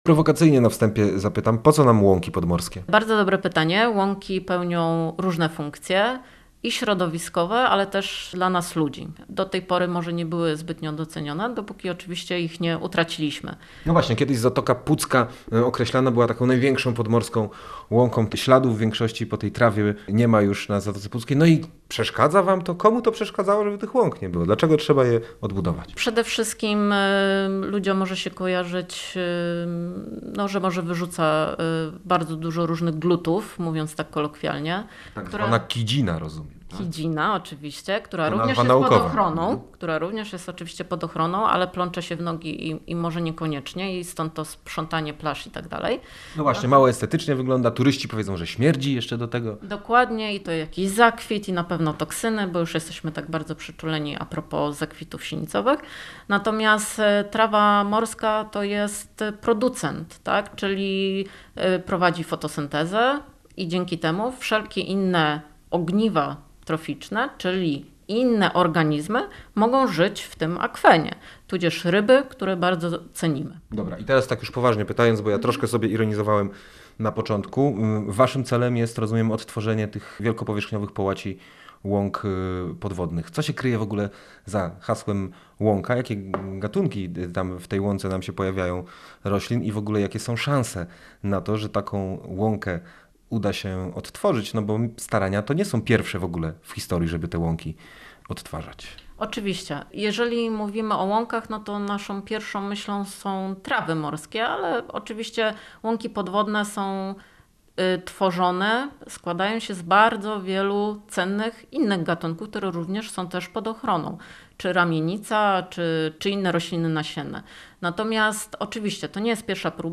Posłuchaj rozmów